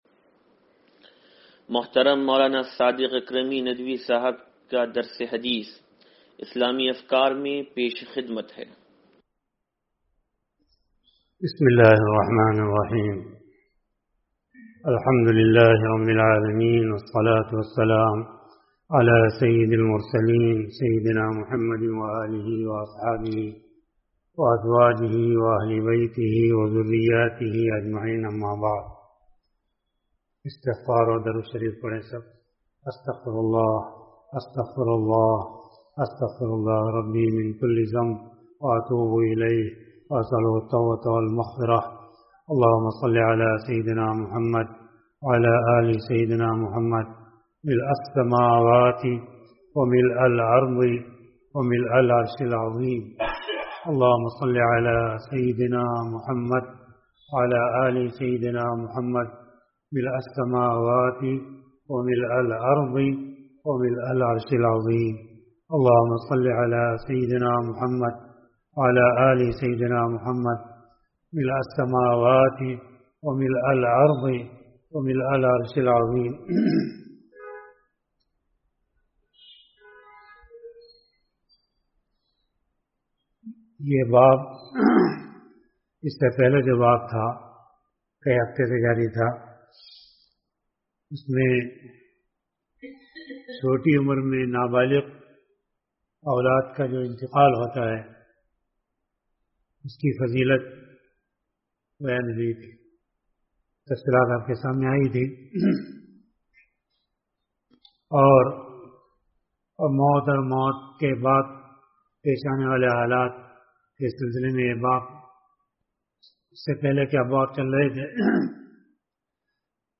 درس حدیث نمبر 0749